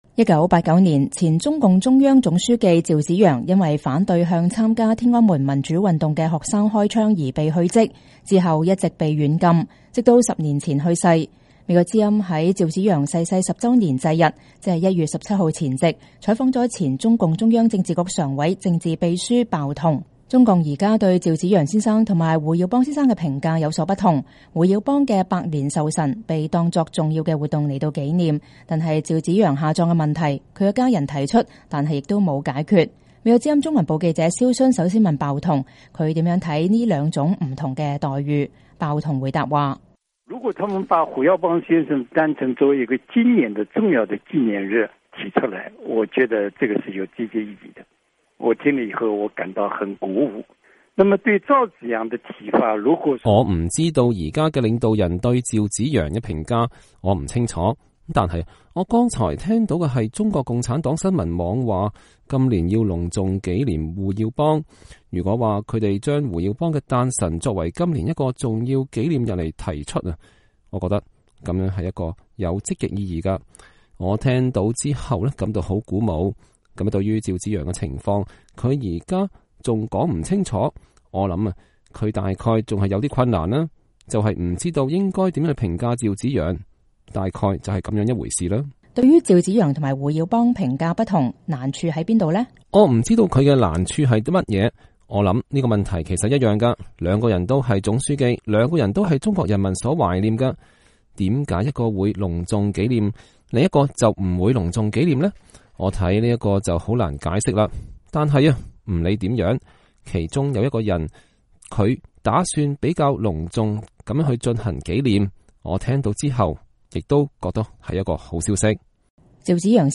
鮑彤訪談錄：趙紫陽影響不可磨滅